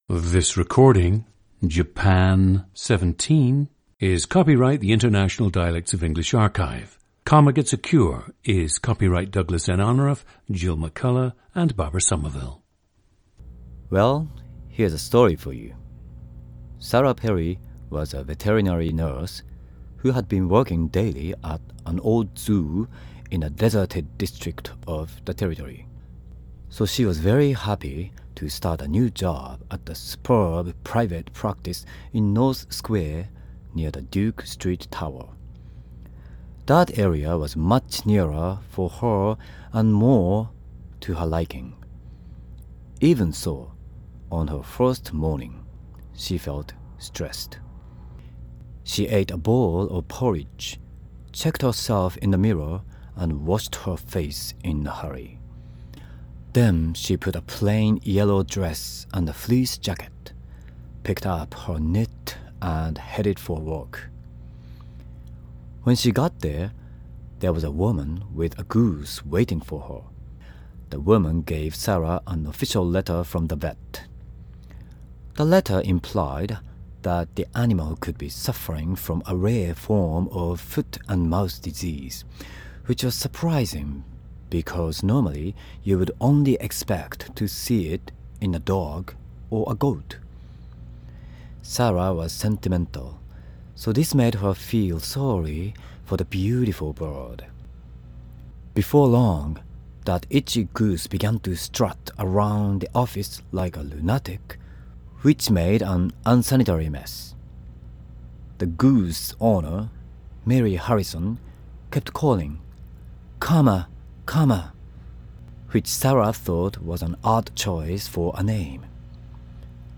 GENDER: male
The speaker believes that his speech has been influenced by both the Kobe dialect of Japanese and the Australian-English dialect.
The recordings average four minutes in length and feature both the reading of one of two standard passages, and some unscripted speech.